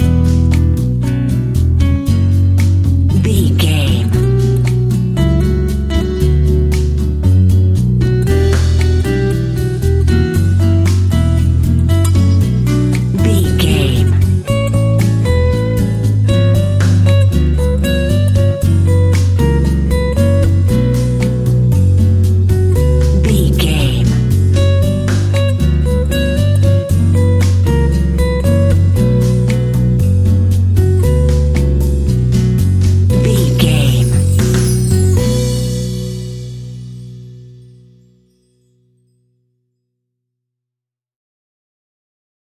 An exotic and colorful piece of Espanic and Latin music.
Aeolian/Minor
romantic
maracas
percussion spanish guitar